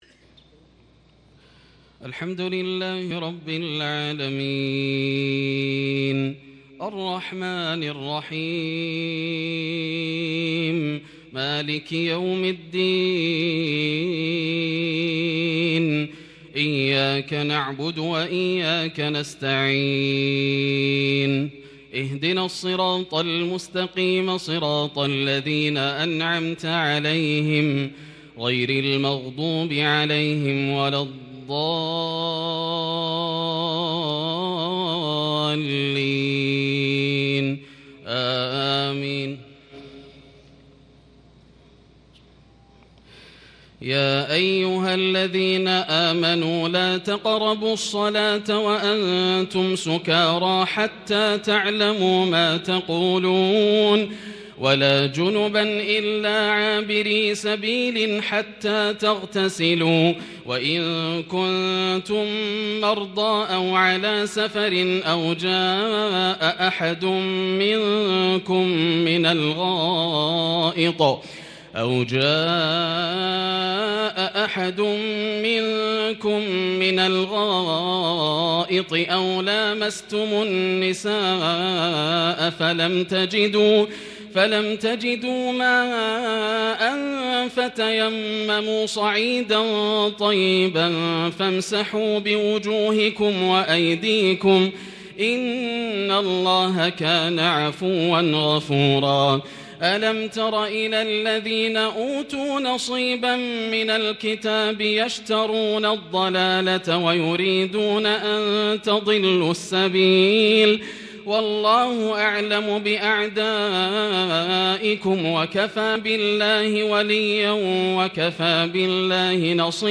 تراويح ليلة 6 رمضان 1441هـ سورة النساء (43-87) Taraweeh 6st night Ramadan Surah An- Nisa 1441H > تراويح الحرم المكي عام 1441 🕋 > التراويح - تلاوات الحرمين